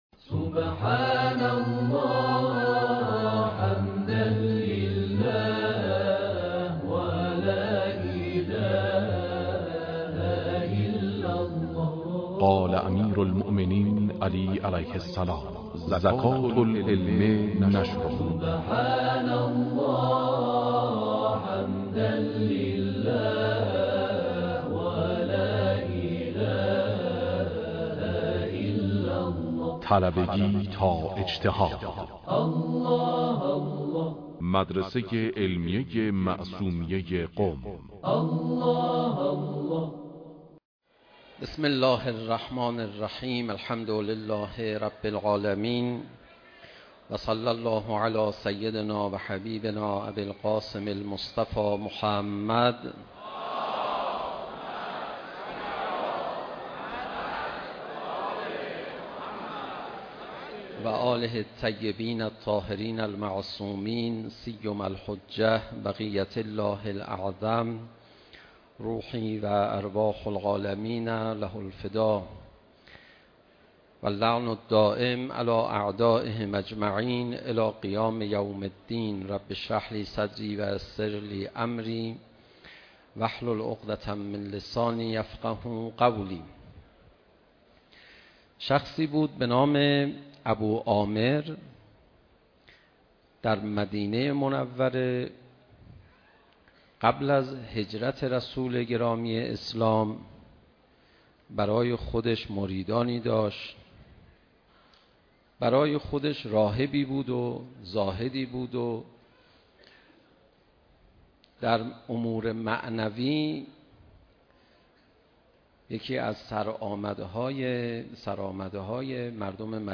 سخنرانی استاد پناهیان در بیت رهبری محرم 1394.mp3